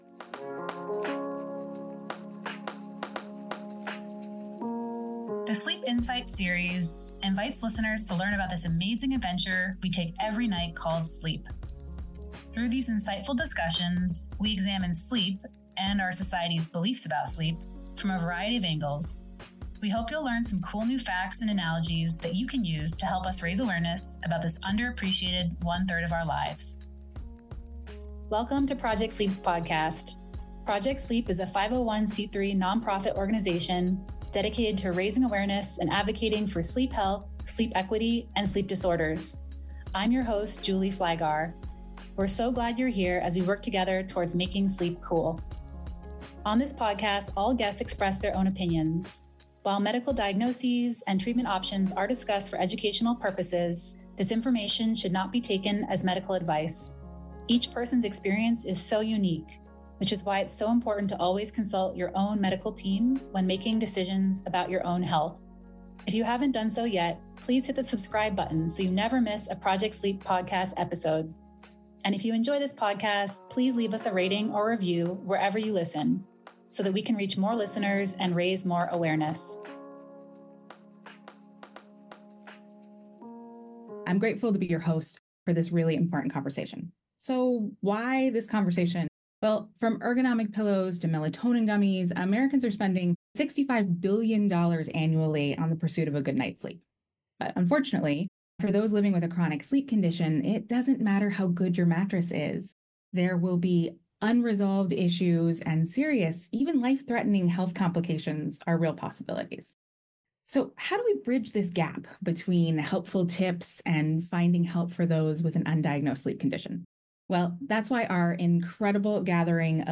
This conversation informed and educated media professionals on how to accurately cover sleep health and sleep disorders. In this conversation, people living with different sleep disorders shared insights from their journeys and what they wish they had read.